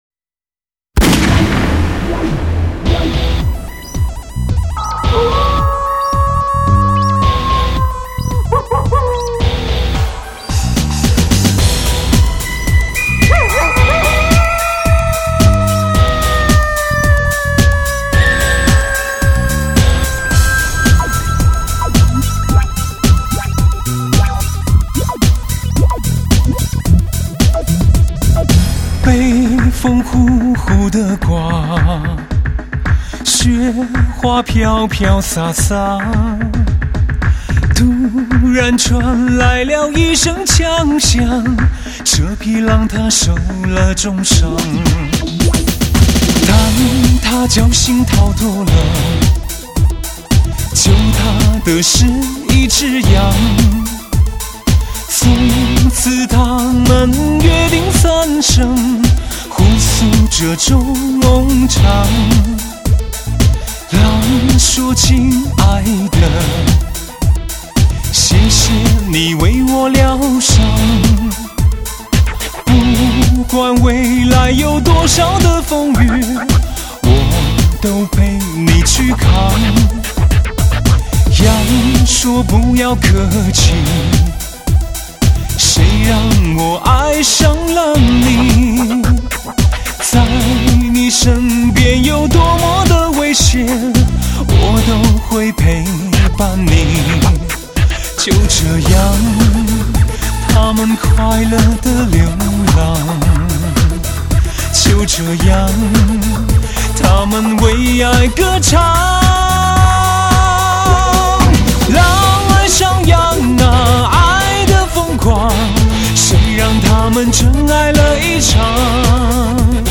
DISCO发烧天碟 DSD
它的音效动态更大更明显，音场更宽广、透明。
男女歌手典范的音质与让人发酥的情迷演绎。
飘渺的歌声，丝绸般的爽滑质感，
大胆创新的士高动感发烧精品！